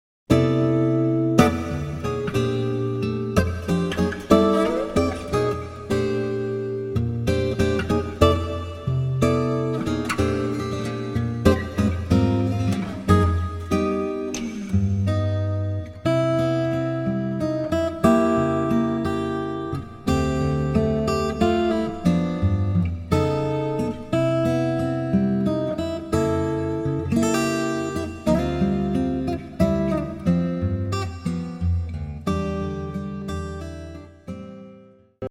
ALBERT&MUELLER S-6 Jacaranda. Strings : D'addario EJ-26